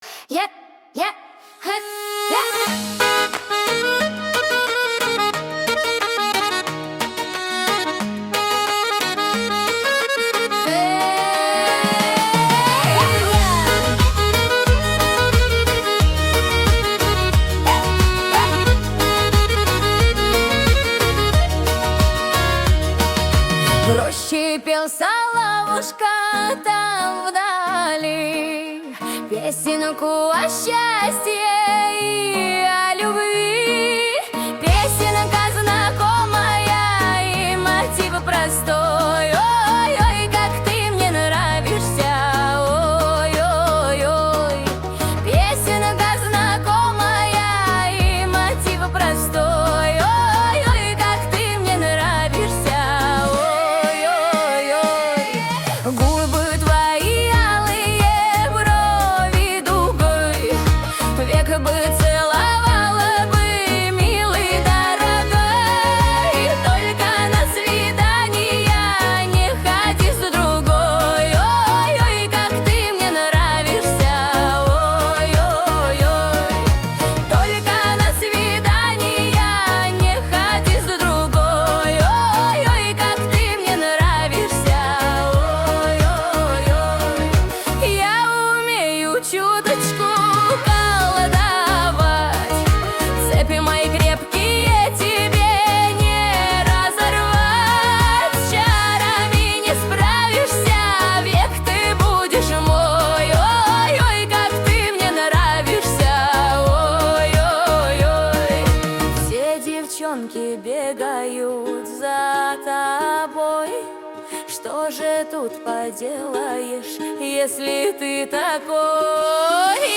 Народные песни